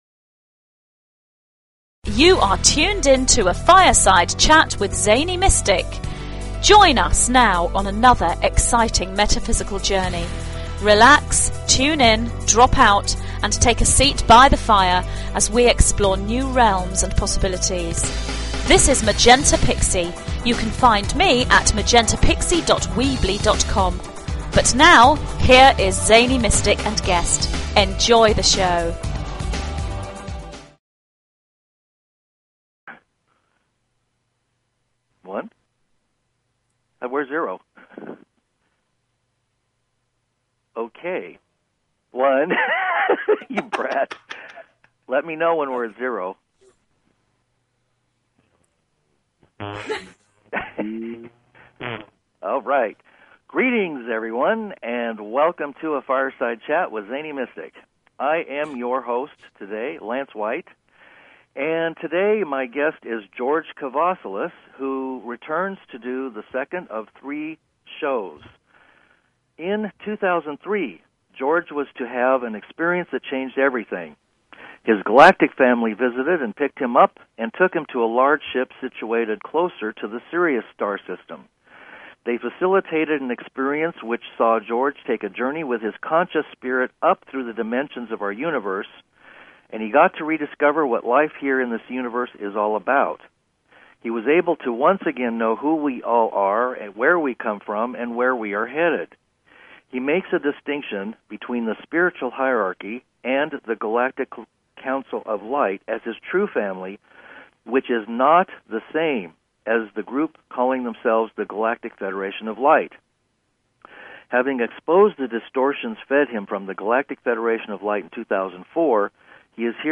This interview builds upon the first, and goes deeper into the cosmic mysteries and the divine plan of the Creator of this Universe.